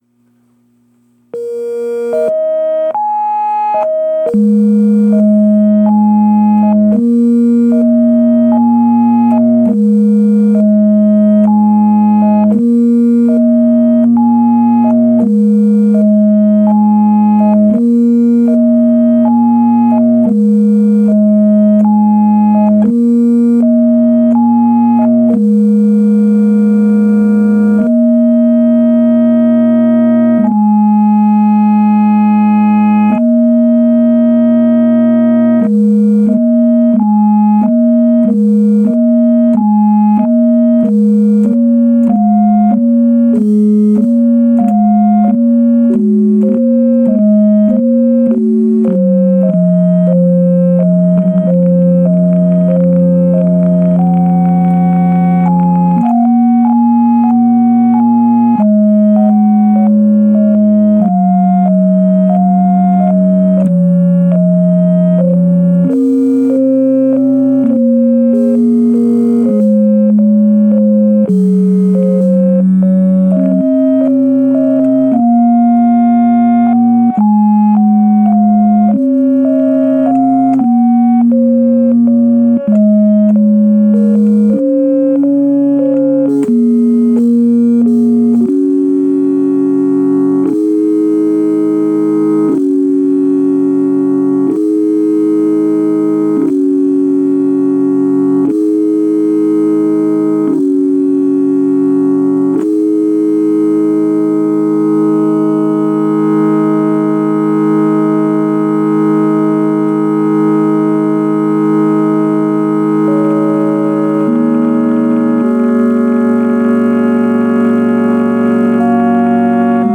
playing their hearts out on the casio keyboard